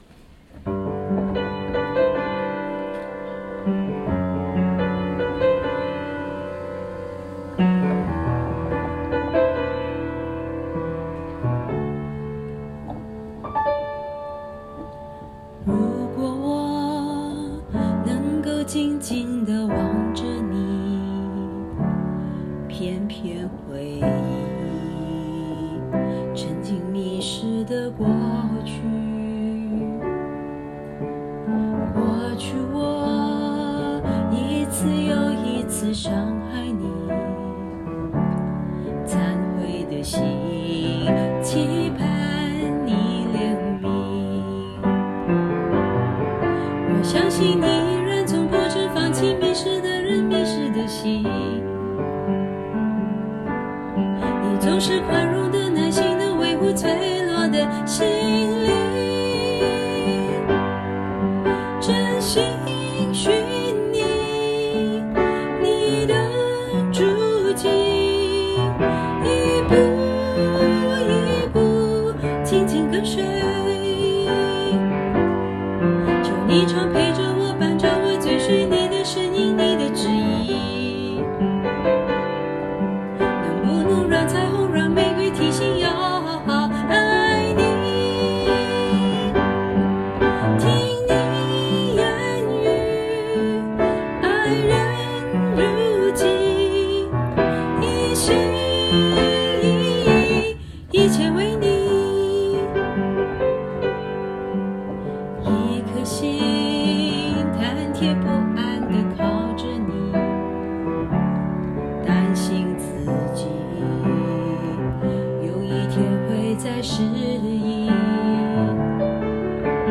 【原创圣歌】|《心灵》